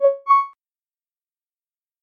Звуки подключения, отключения
Звук подключения подтвержден